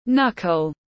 Knuckle /ˈnʌk.əl/
Knuckle.mp3